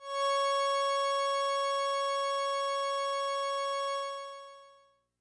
描述：通过Modular Sample从模拟合成器采样的单音。
Tag: CSHARP 6 MIDI音符-85 罗兰-HS-80 合成器 单票据 多重采样